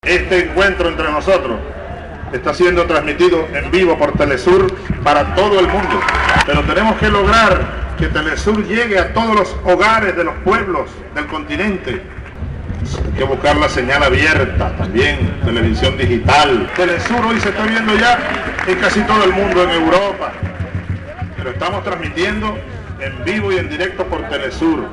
Señaló el presidente venezolano Hugo Chavez Frías al recibir, en el marco de un acto multitudinario, el «Premio Rodolfo Walsh» por su compromiso como «Presidente Latinoamericano por la Comunicación Popular»
El acto tuvo lugar en el predio de la Facultad, donde se levantó un escenario para la ocasión, al que concurrieron personalidades del ámbito local, funcionarios de naciones latinoamericanas y una multitud de jóvenes que le plasmó a la noche un clima de emoción y alegría.